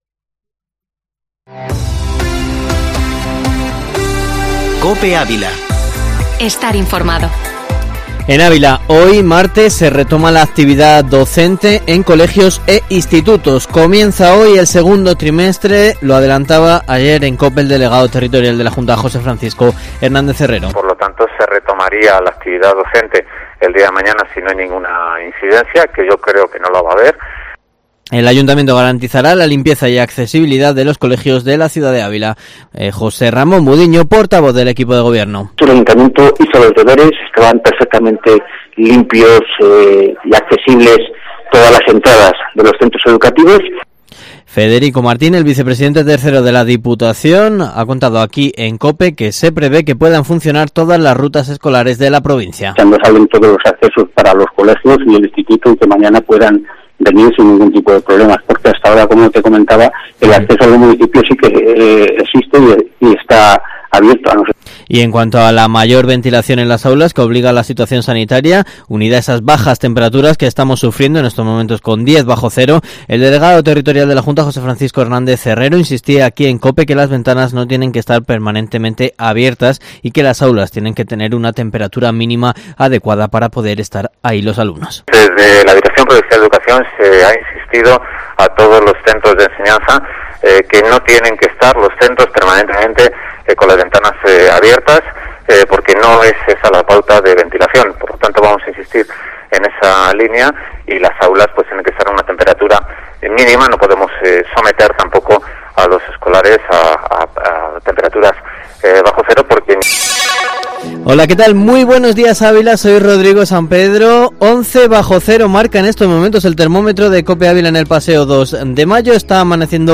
Informativo matinal Herrera en COPE Ávila 12/01/2021